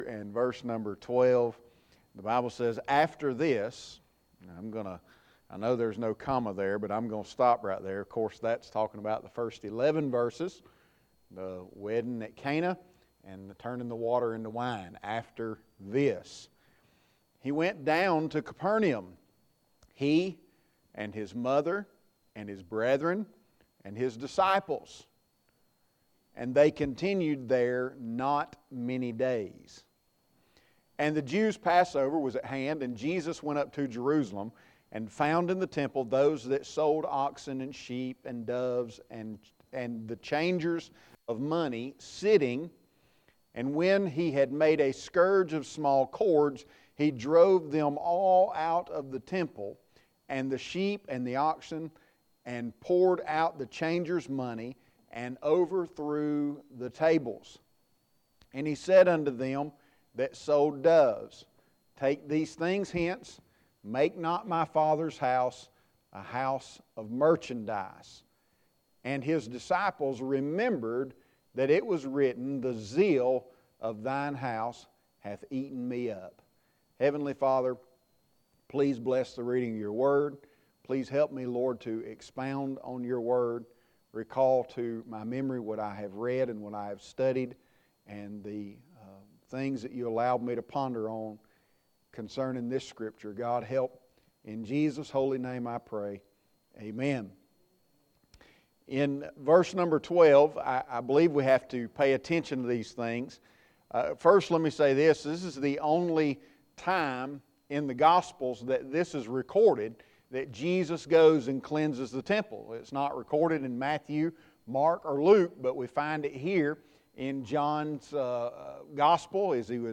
Sermons | Gateway Baptist Church